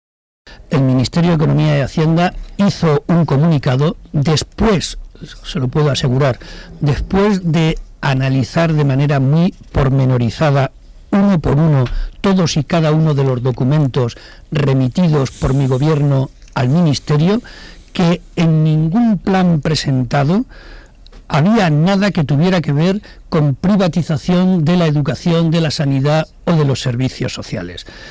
El Presidente del Grupo Parlamentario y secretario general de los socialistas de Castilla-La Mancha, José María Barreda, ha realizado una entrevista en el programa radiofónico “Herrera en la Onda”, de Onda Cero.